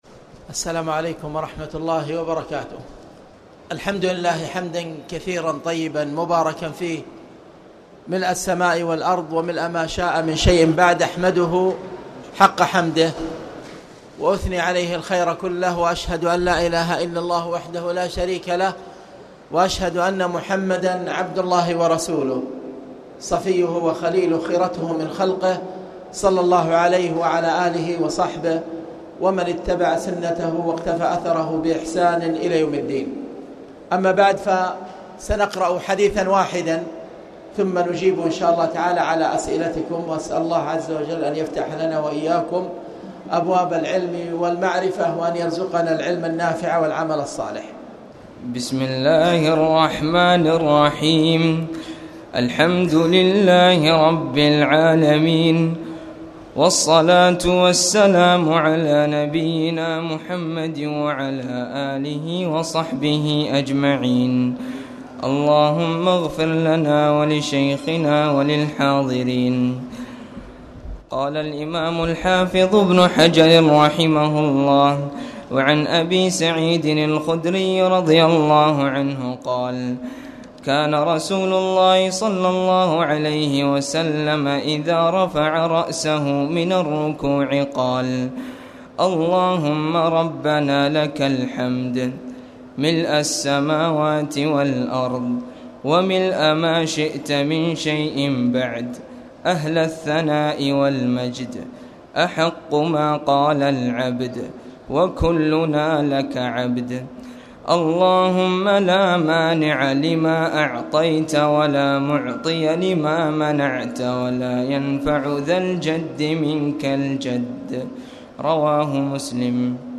تاريخ النشر ١٦ رمضان ١٤٣٨ هـ المكان: المسجد الحرام الشيخ